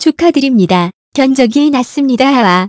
그래서 전문 성우의 목소리로 문장을 읽어주는 TTS 소프트웨어를 찾아봤는데, 마침 250자까지는 공짜(?)로 되는 착한 곳이 있더군요~
따라서 위의 TTS 프로그램으로 소리를 내면서 동시에 GoldWave로 녹음을 하면 되는 것입니다.